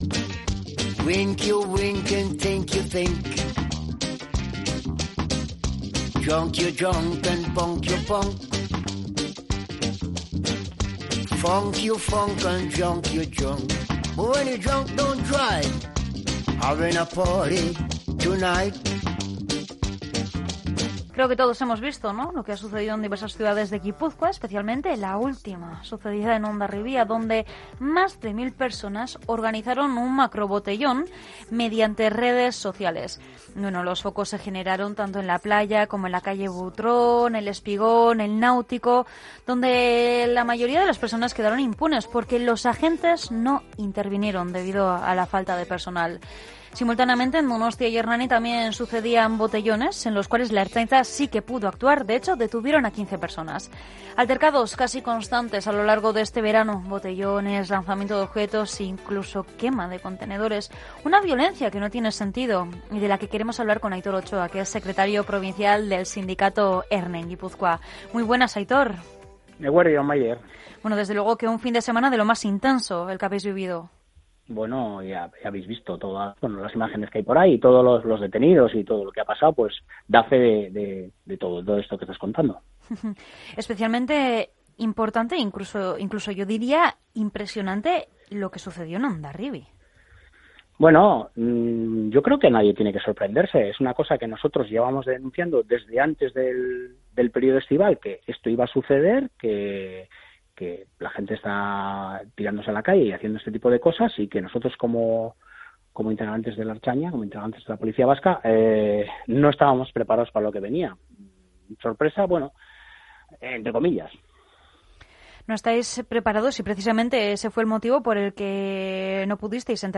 El alcalde de Hondarribia, Txomin Sagarzazu, los vecinos de la localidad y la Ertzaintza hablan de los disturbios del domingo - Onda Vasca
Tomando el pulso a la actualidad en el mediodía más dinámico de la radio.